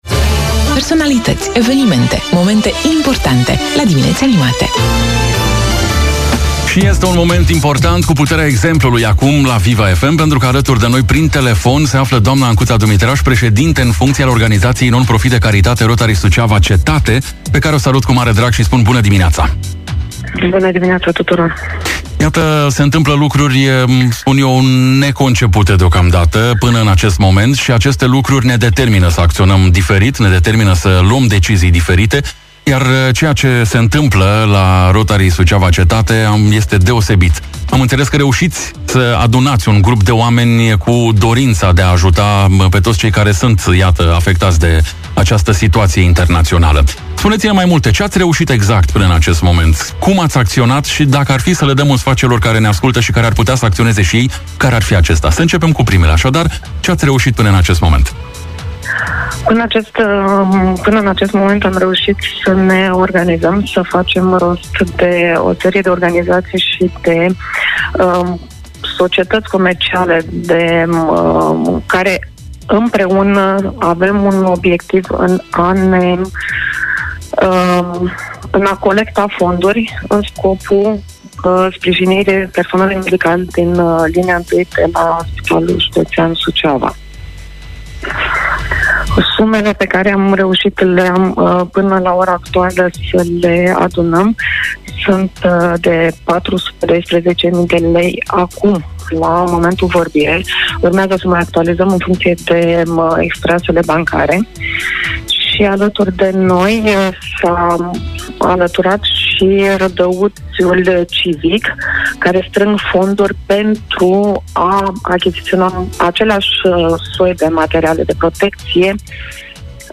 prin telefon